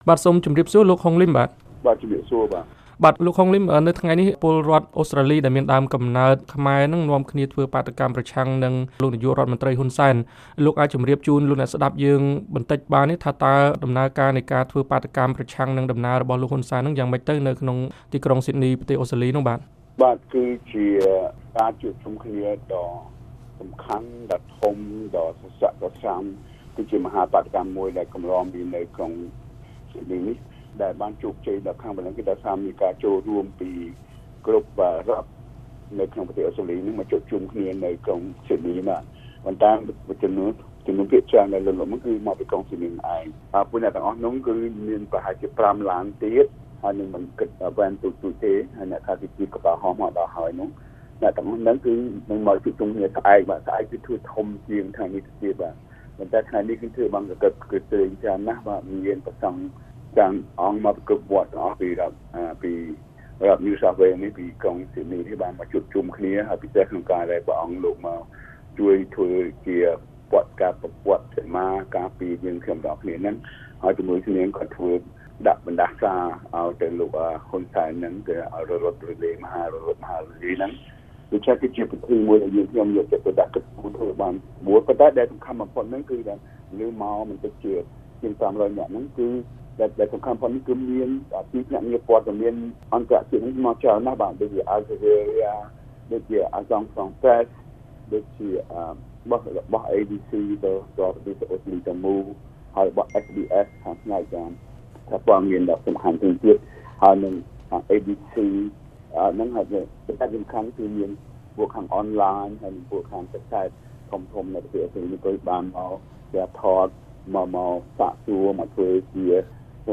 បទសម្ភាសន៍ VOA៖ លោក ហុង លីម ថា វត្តមានរបស់លោក ហ៊ុន សែន នៅកិច្ចប្រជុំកំពូលអាស៊ាន-អូស្ត្រាលី គឺជារឿងគួរឲ្យអាម៉ាស់